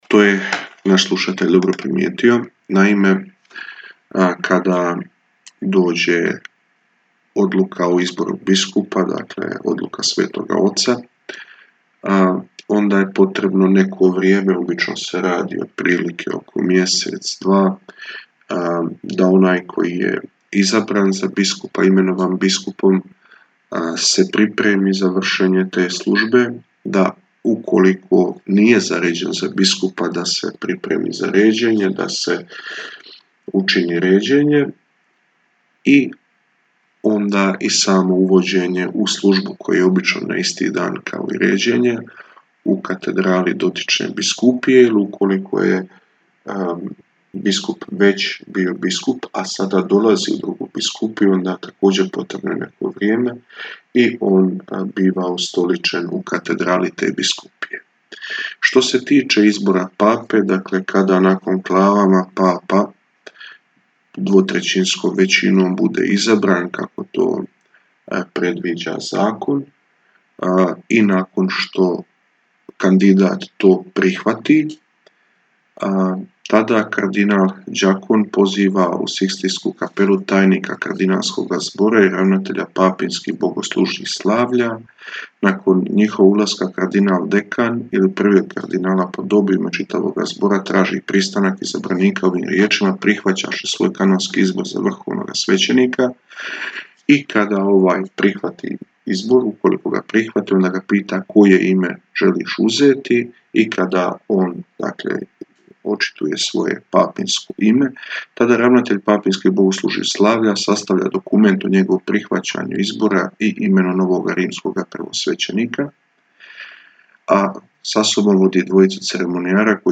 Rubrika ‘Pitajte svećenika’ u programu Radiopostaje Mir Međugorje je ponedjeljkom od 8 sati i 20 minuta, te u reprizi ponedjeljkom navečer u 20 sati. U njoj na pitanja slušatelja odgovaraju svećenici, suradnici Radiopostaje Mir Međugorje.